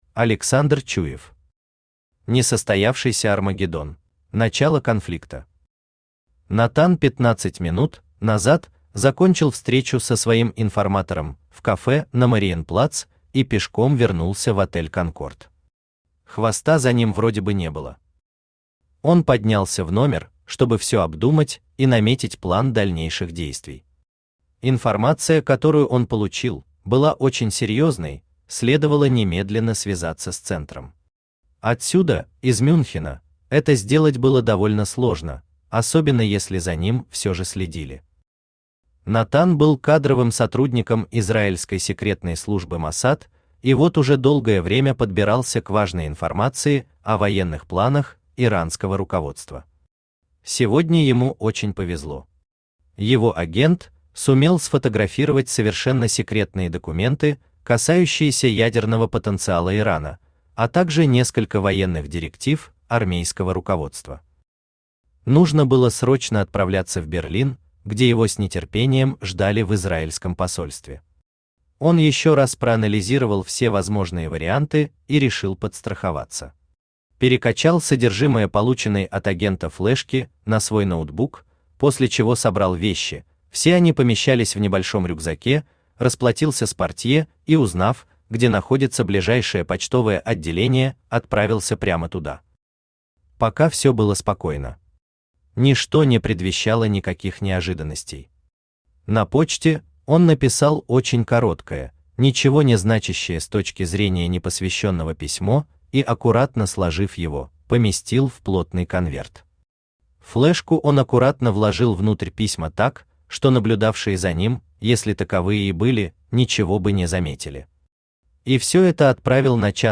Aудиокнига Несостоявшийся Армагеддон Автор Александр Викторович Чуев Читает аудиокнигу Авточтец ЛитРес.